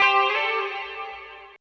137 GTR 7 -R.wav